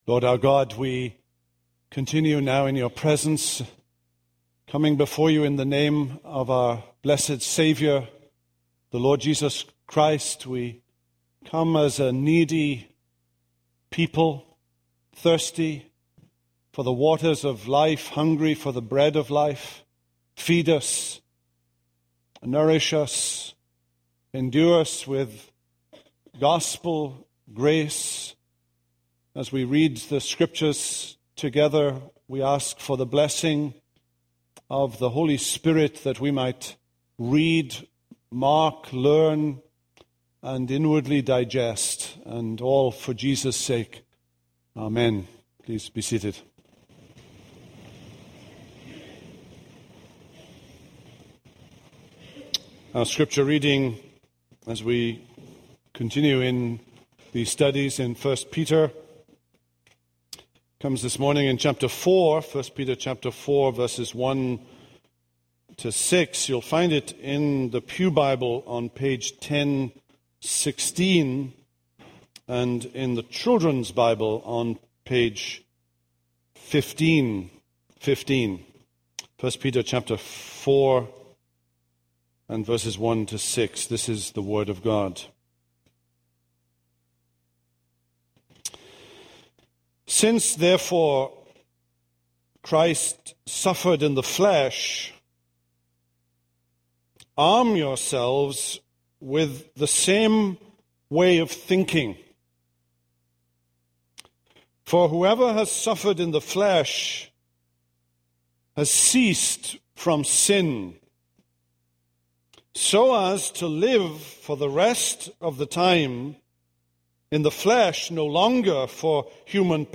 This is a sermon on 1 Peter 4:1-6.